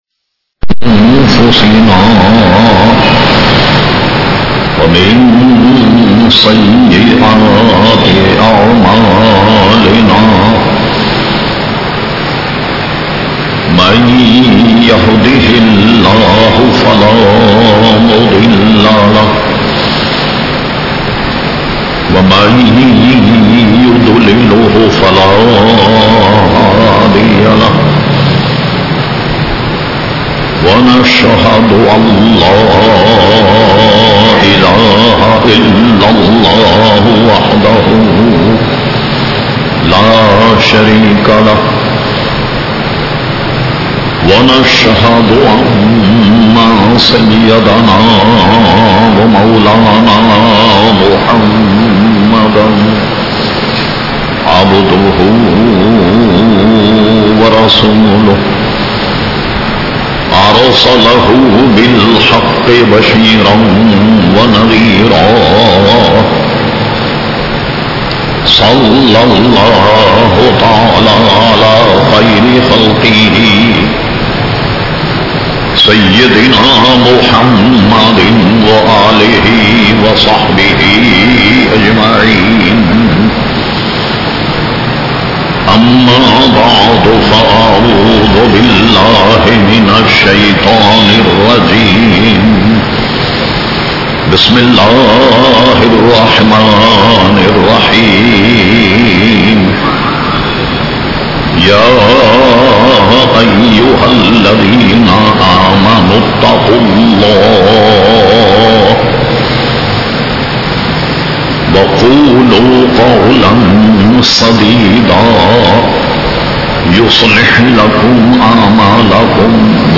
زبان کی حفاظت1 ZiaeTaiba Audio میڈیا کی معلومات نام زبان کی حفاظت1 موضوع تقاریر ضیائے اصلاح آواز سید شاہ تراب الحق قادری نوری زبان اُردو کل نتائج 1172 قسم آڈیو ڈاؤن لوڈ MP 3 ڈاؤن لوڈ MP 4 متعلقہ تجویزوآراء